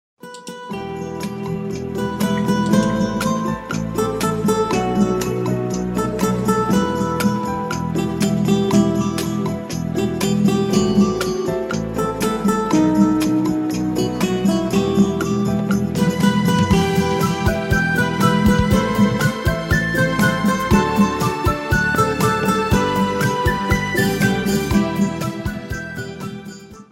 Мне кажется, что он просто он читает стихи под эту мелодию.